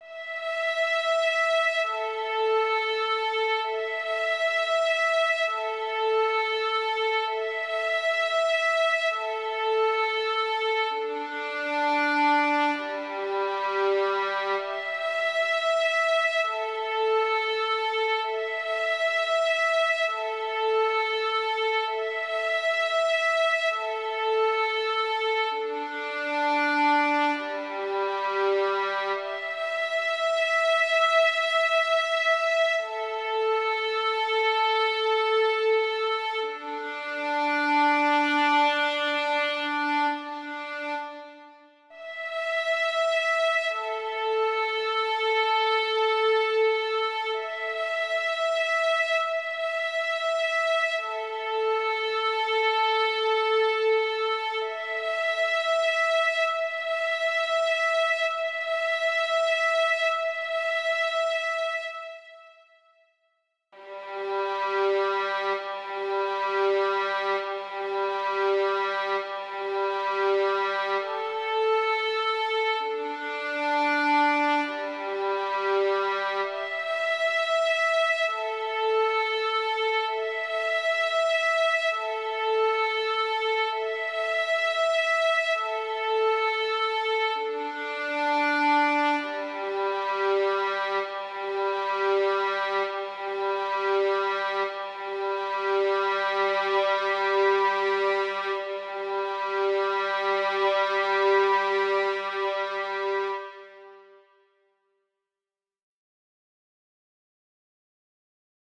1-1 Compositions For Violin And Piano > 바이올린 | 신나요 오케스트라